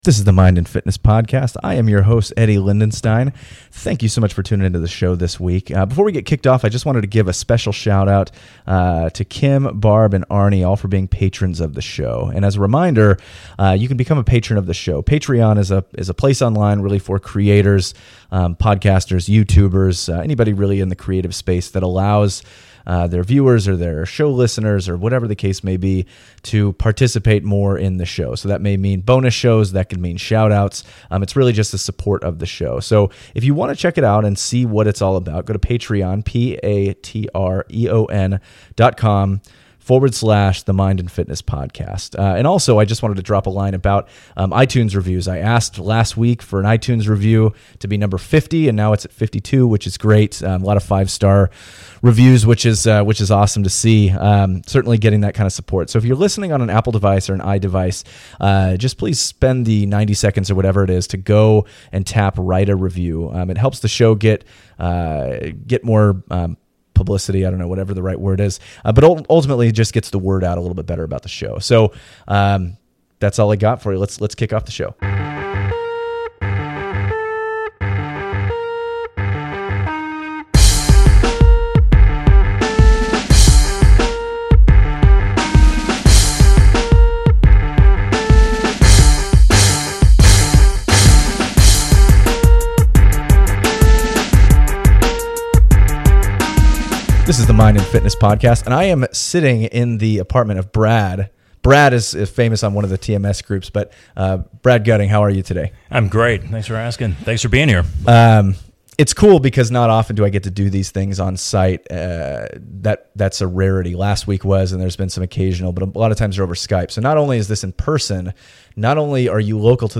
at his apartment to sit down and talk for nearly an hour and a half